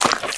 STEPS Swamp, Walk 05.wav